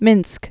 (mĭnsk)